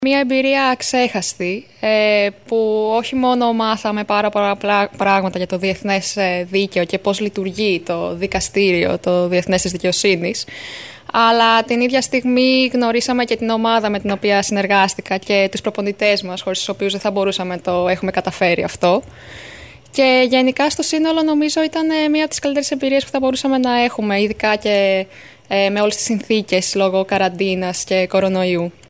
φιλοξενήθηκαν σήμερα στην ΕΡΤ Κομοτηνής και στην εκπομπή «Καθημερινές Ιστορίες»